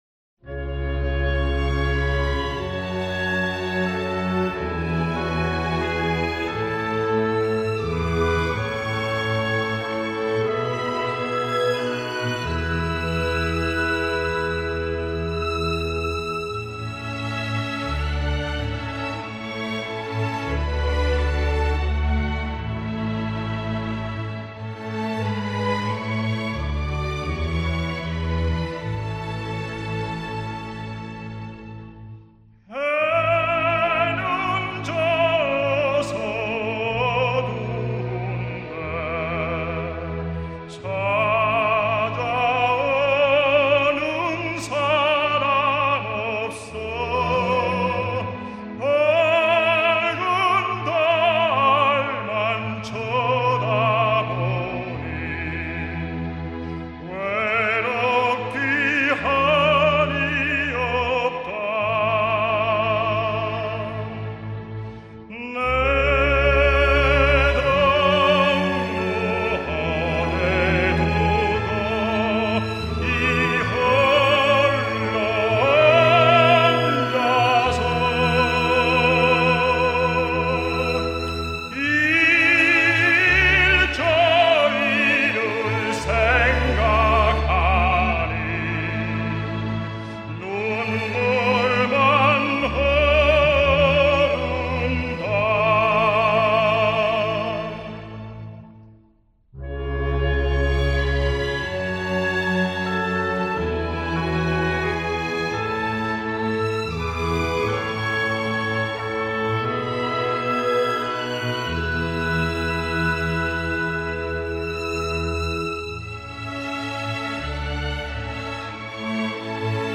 Ten